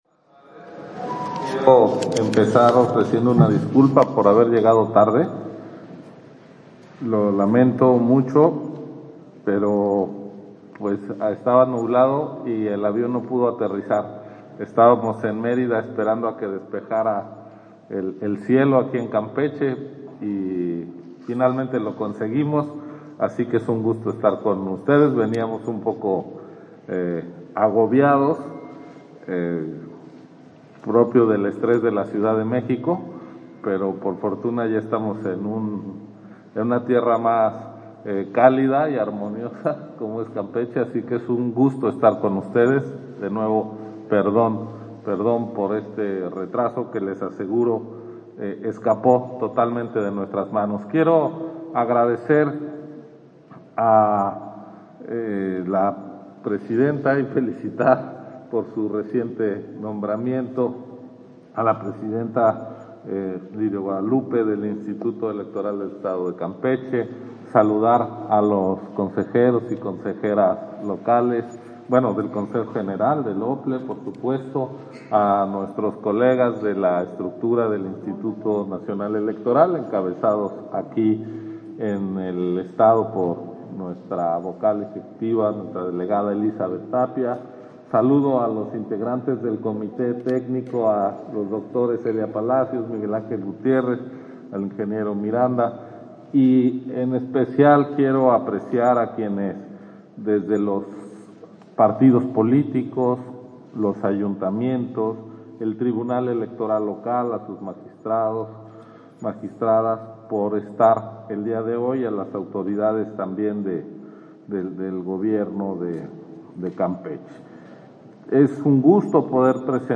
Intervención de Ciro Murayama, en el Foro Estatal de Distritación Nacional Electoral 2021-2023